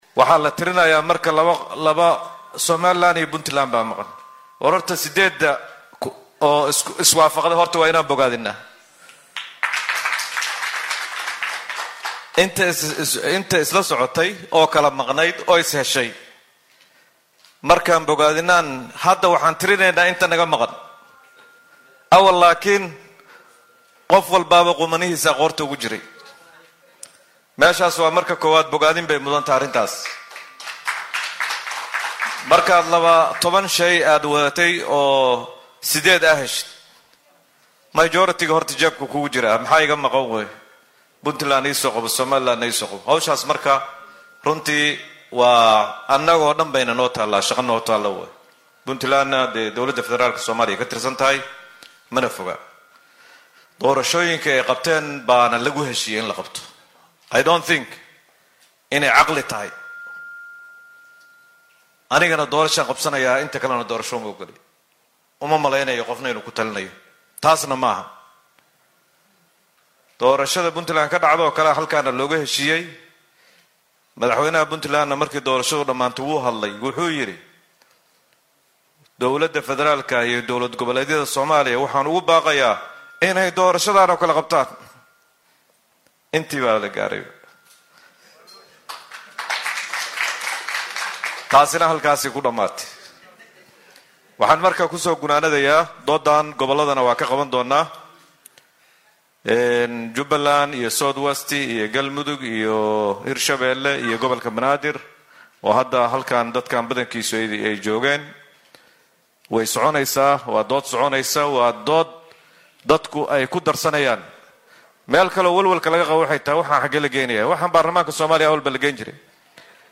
Wasiirka wasaaradda arrimaha gudaha ee Soomaaliya Axmad Macallin Fiqi ayaa arrintan ka hadlay.